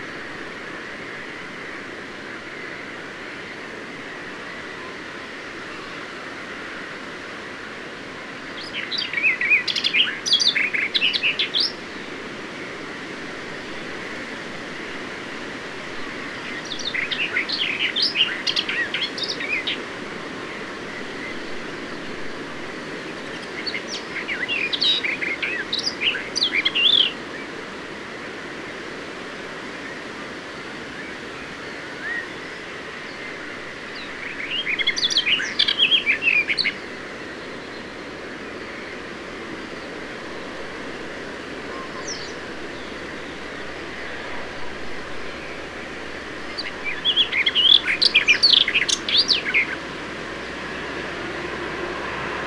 In a hedge two birders found a singing Barred Warbler Sylvia nisoria.
100524, Barred Warbler Sylvia nisoria, song, Neusiedler See, Austria
barred_warbler.mp3